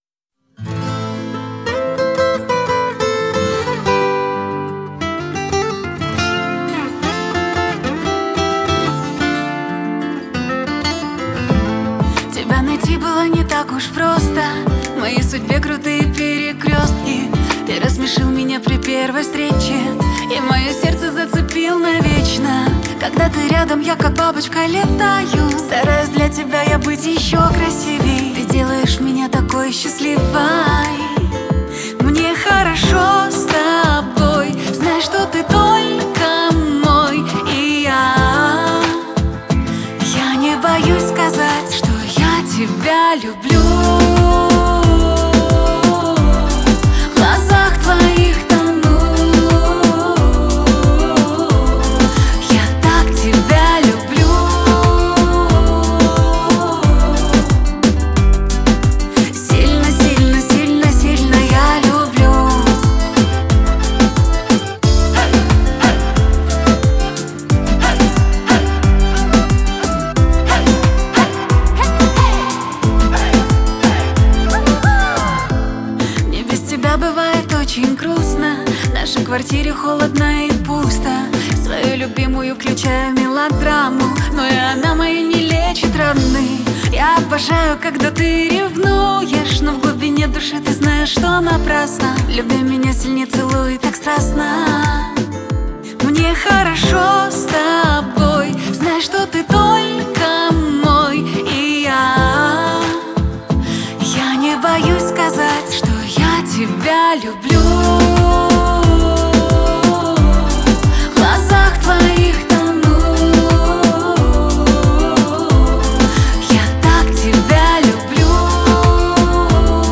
поп-песня